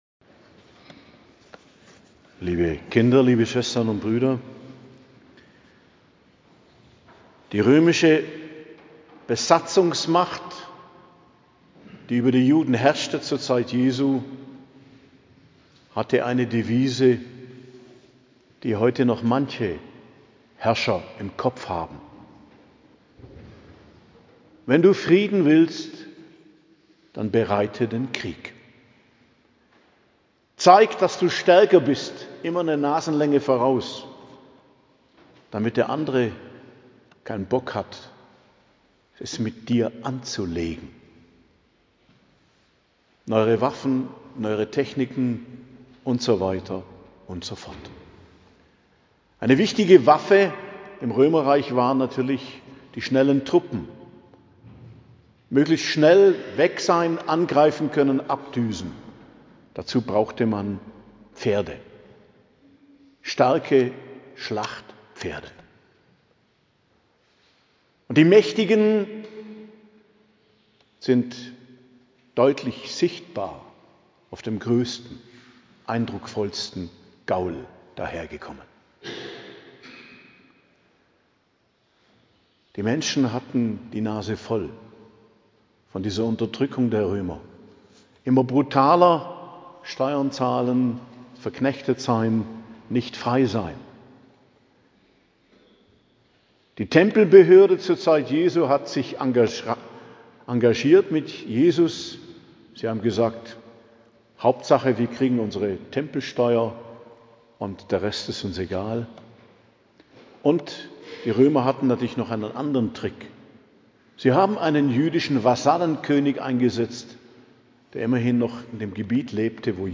Predigt zum Palmsonntag am 13.04.2025 ~ Geistliches Zentrum Kloster Heiligkreuztal Podcast